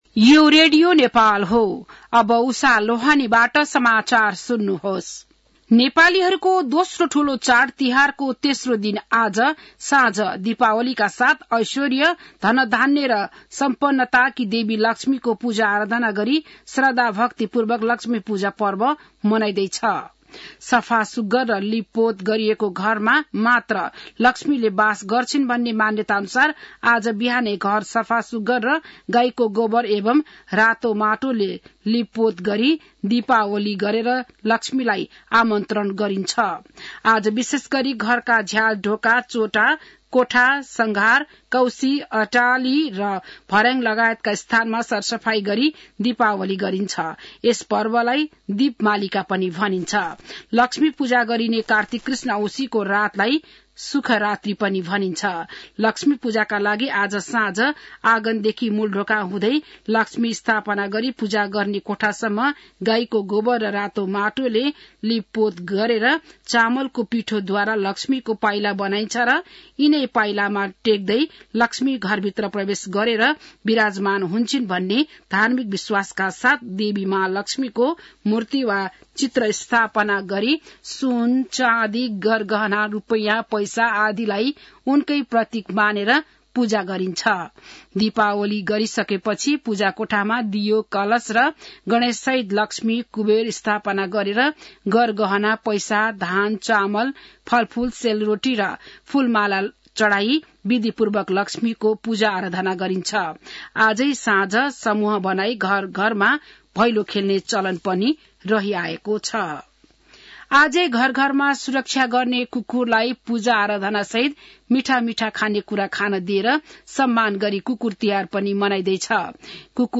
बिहान १० बजेको नेपाली समाचार : १६ कार्तिक , २०८१